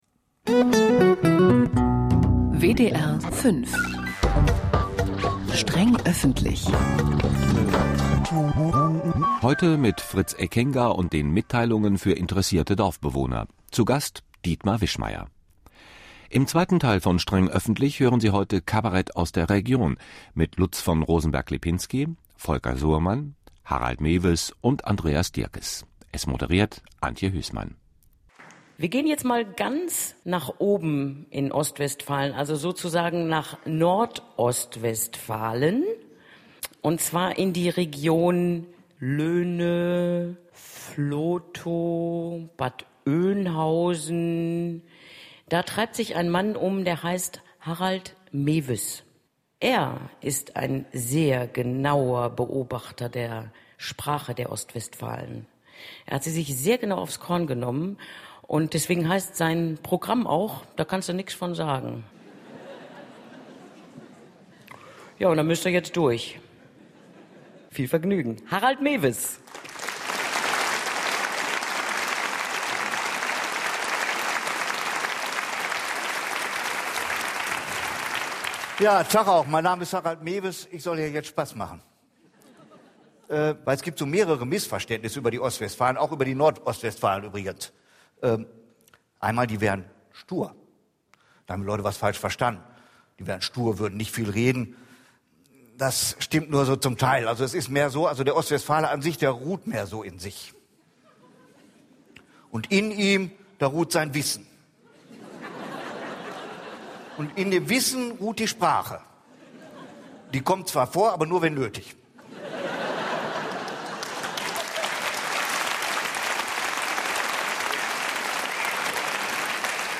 Kabarett
Live Mitschnitt aus der Paderhalle